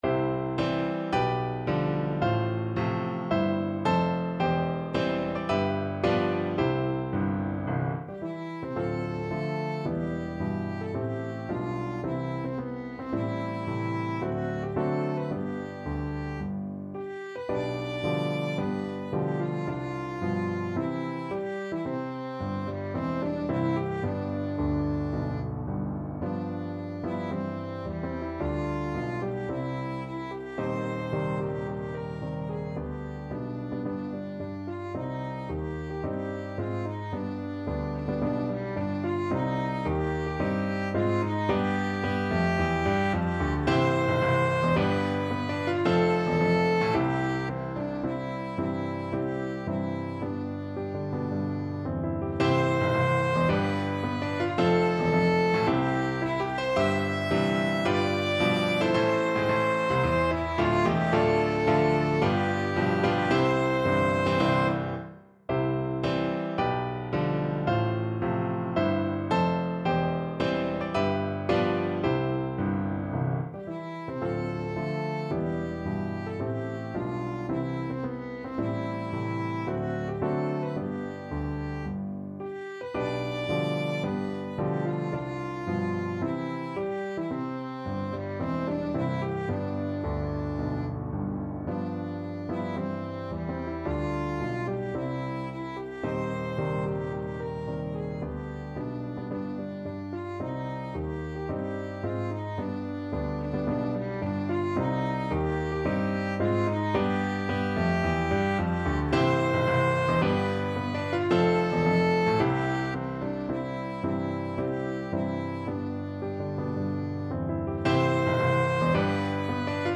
Violin
C major (Sounding Pitch) (View more C major Music for Violin )
4/4 (View more 4/4 Music)
Marcial = 110 Marcial